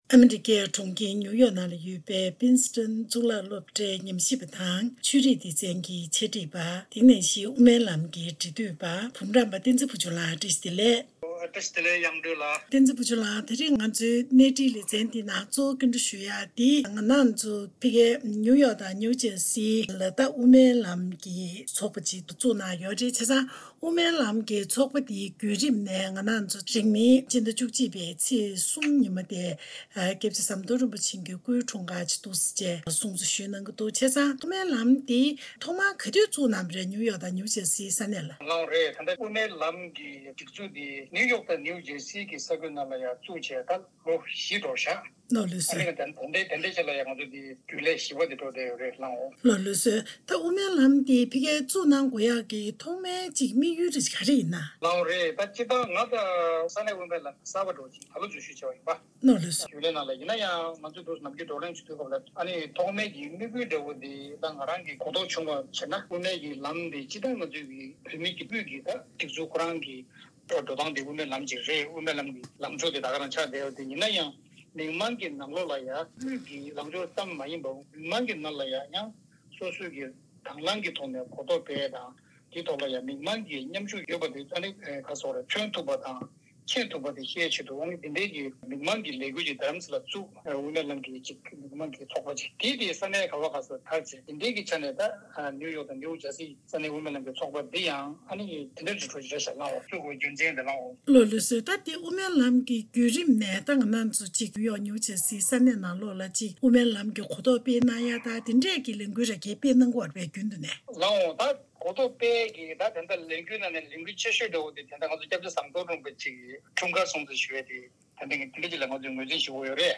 ཐད་ཀར་གནས་འདྲི་ཞུས་པ་ཞིག་གསན་རོགས་གནང་།